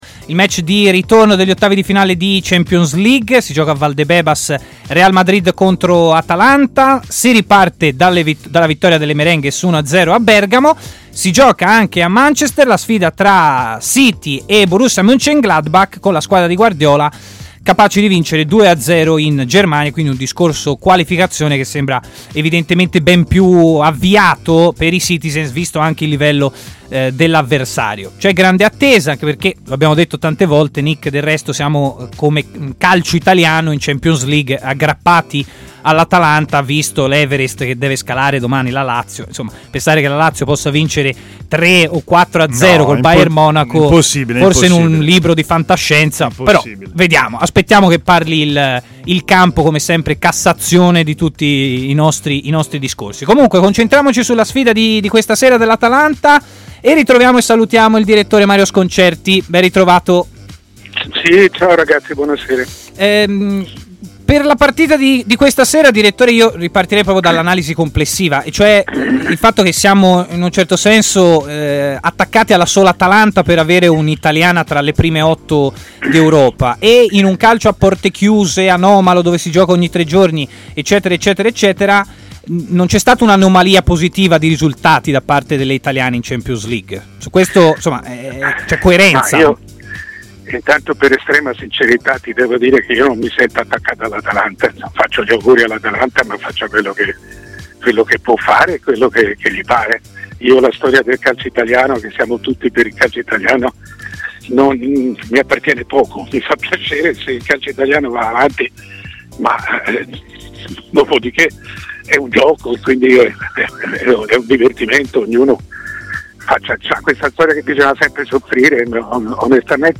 Mario Sconcerti, firma di punta del giornalismo sportivo italiano e opinionista di TMW Radio